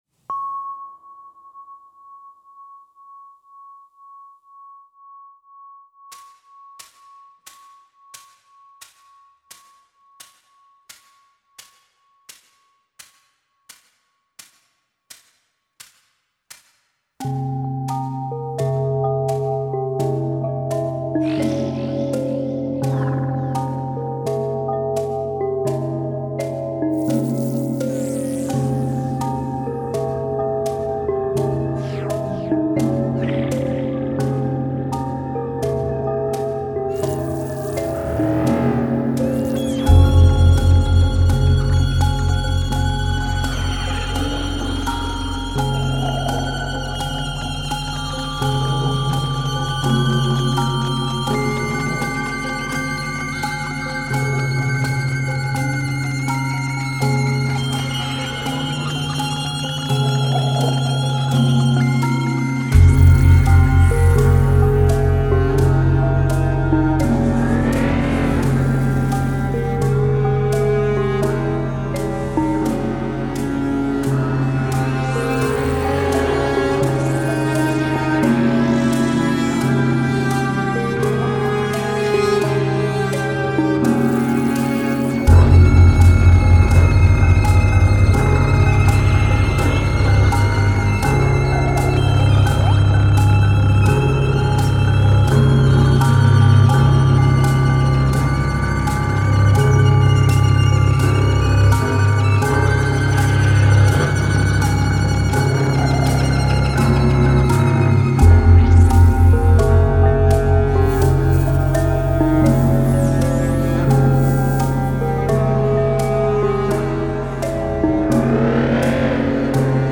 A new harmonic language